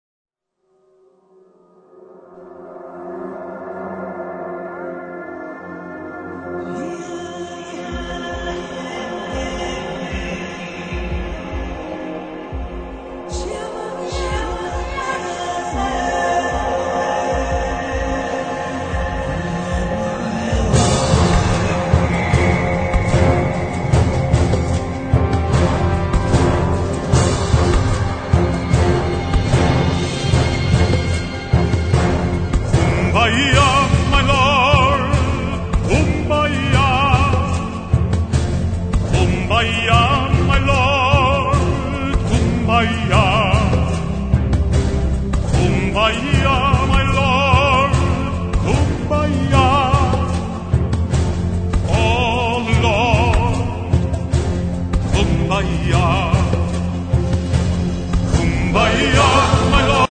Piano Score
key: F-major